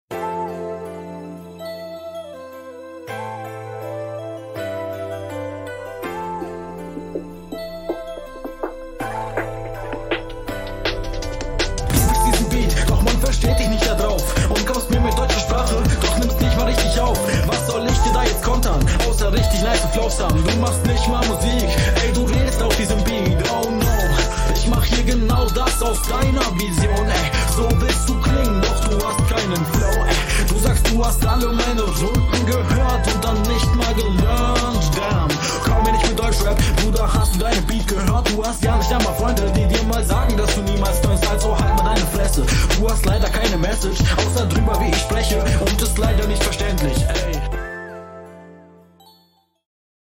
Einstieg find ich Whack - weil man dich echt deutlich schlechter versteht als man deinen …